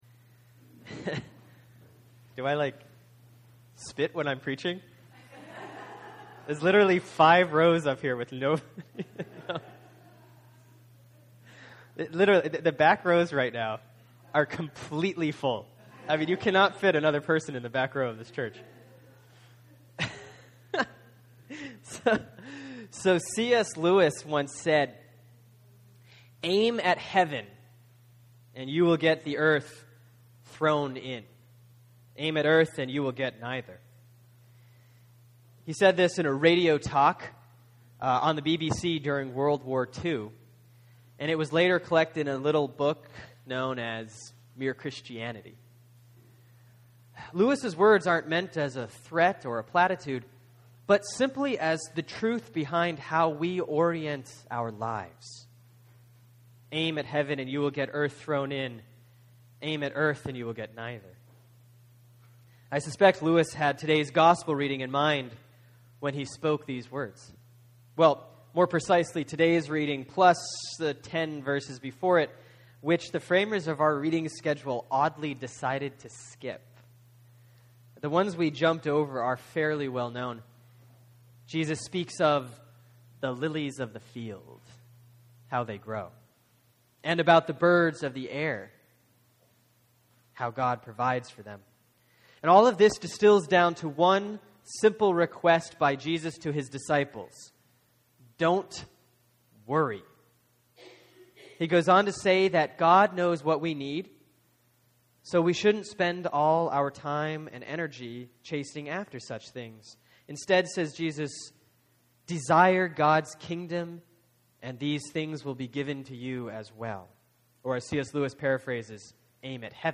(Sermon for Sunday August 11, 2013 || Proper 14C || Luke 12:32-40)